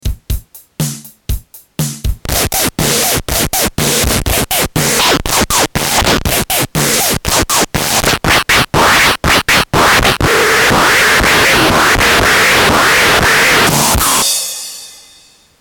这个绿不拉几的玩音是个八度类的单块，用ZAKK的话说，它拥有放屁一样的声音！在4MS里它们叫噪音类效果器（也确实很噪音）